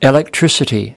6. electricity (n) /ɪˌlekˈtrɪsəti/: điện, điện lực